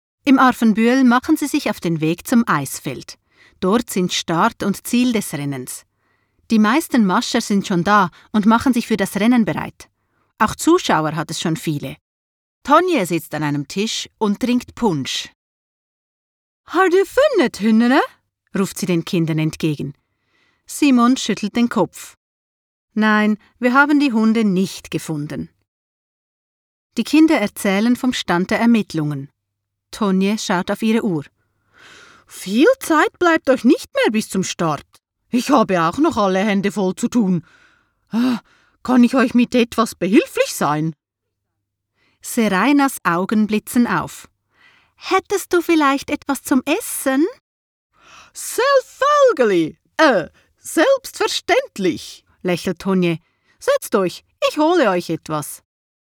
Lektor
niemiecki szwajcarski